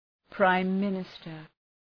Προφορά
{,praım’mınıstər}